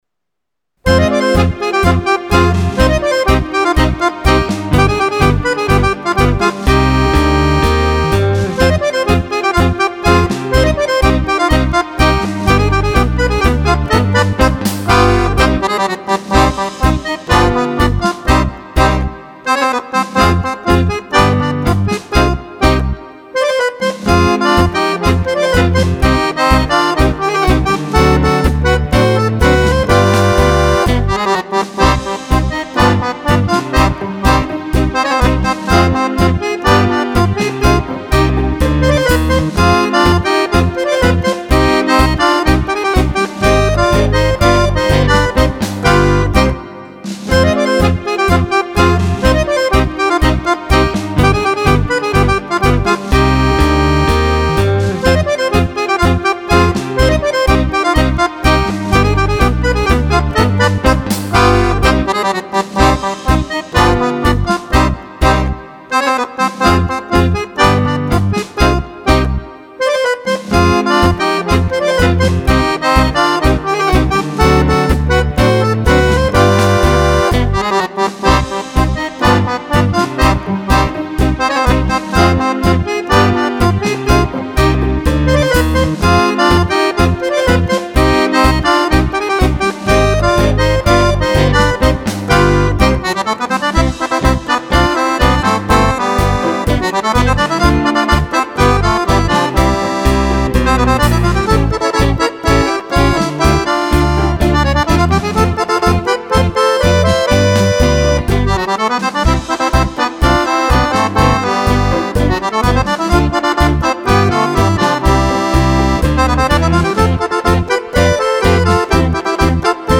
Tango
Tango per Fisarmonica